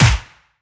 • Verby Steel Snare Drum Sample A# Key 105.wav
Royality free acoustic snare tuned to the A# note. Loudest frequency: 3314Hz
verby-steel-snare-drum-sample-a-sharp-key-105-Sb8.wav